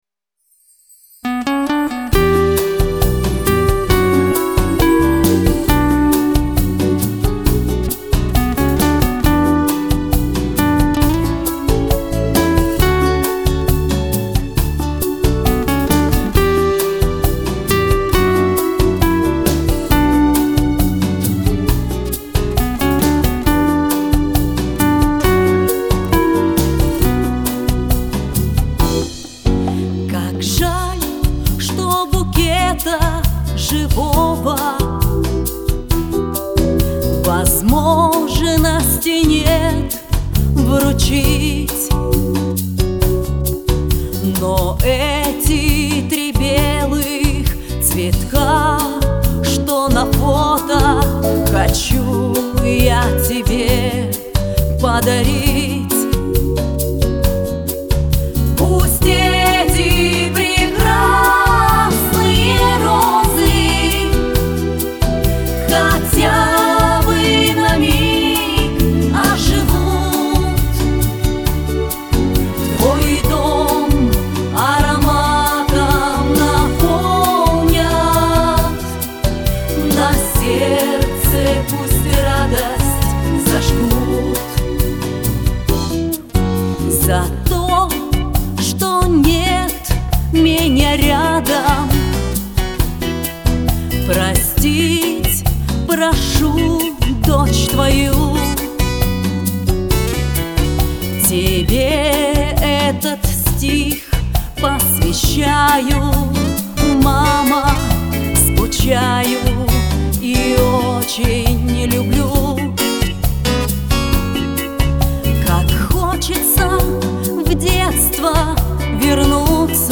Главная  » Архив  » Новое в шансоне